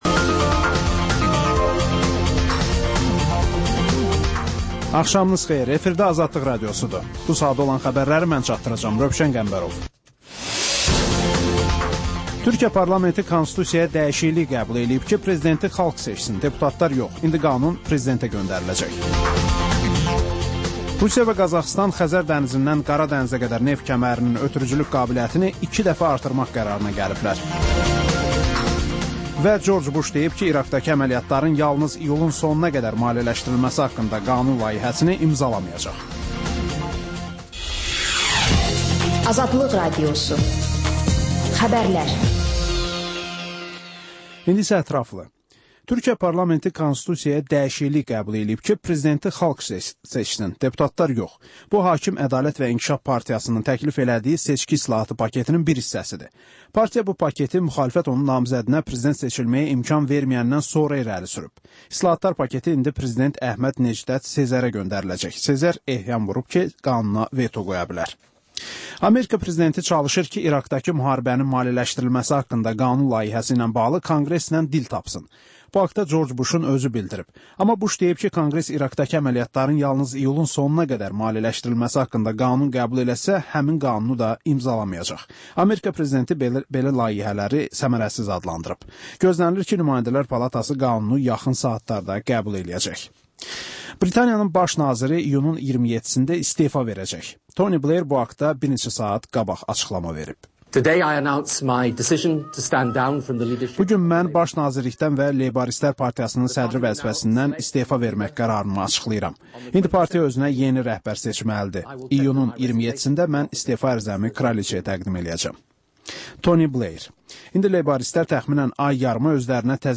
Xəbərlər, müsahibələr, hadisələrin müzakirəsi, təhlillər, sonda TANINMIŞLAR verilişi: Ölkənin tanınmış simalarıyla söhbət